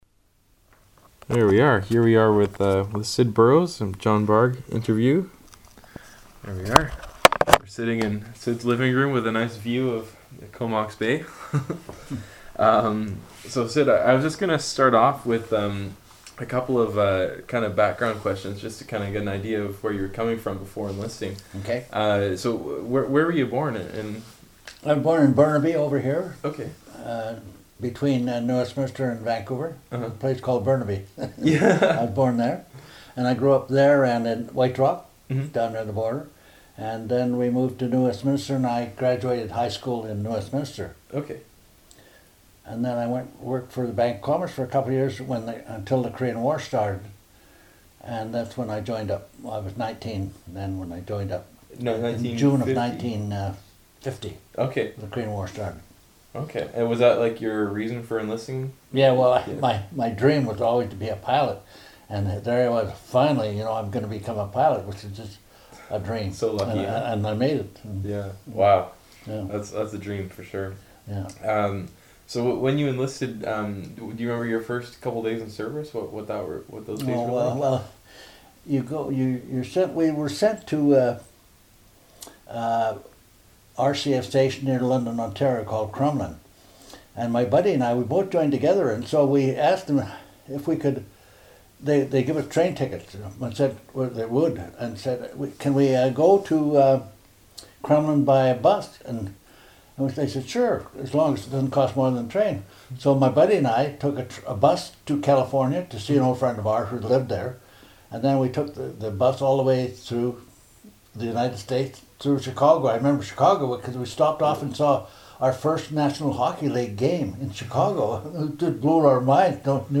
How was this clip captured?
Unfortunately, the recorder stopped near the end of the interview unbeknownst to either the interviewer or interviewee.